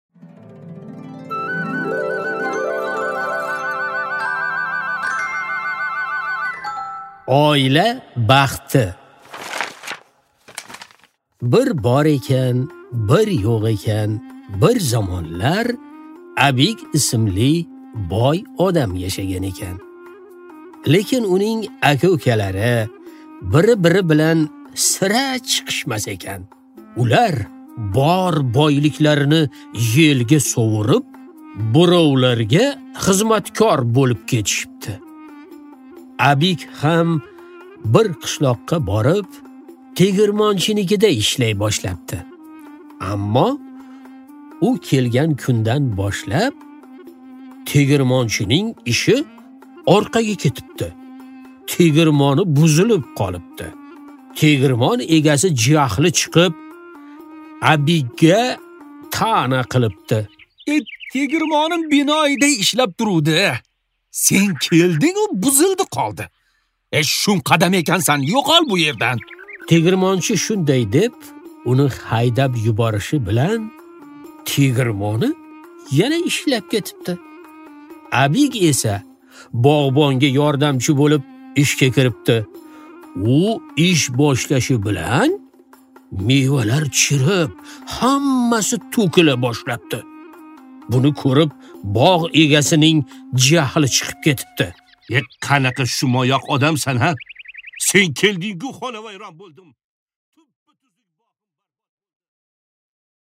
Аудиокнига Oila baxti